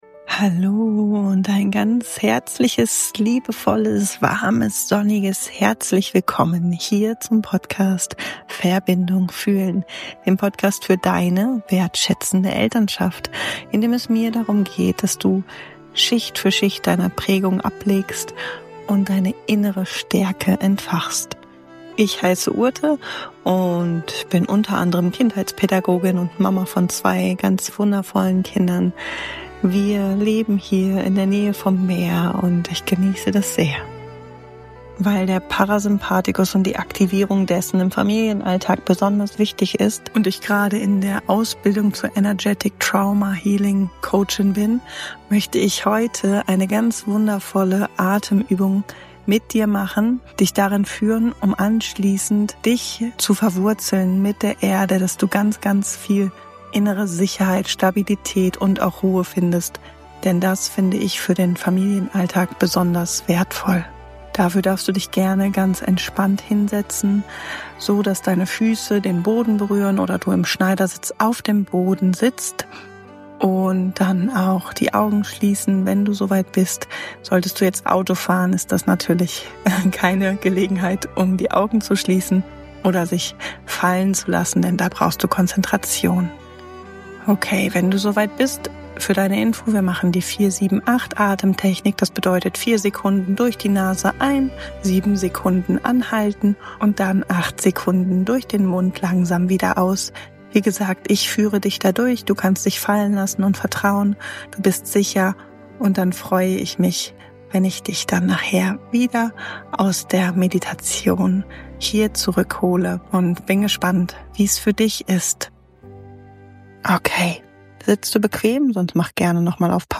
Eine Atem- und Visualisierungsübung für deine Stabilität, Erdung und Verbindung.